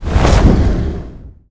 FireExplosion.wav